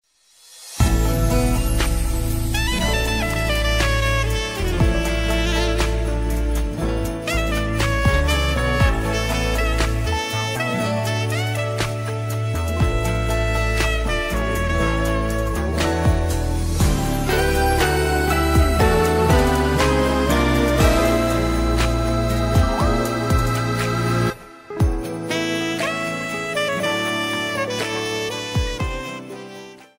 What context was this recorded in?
Cropped to 30 seconds with fade-out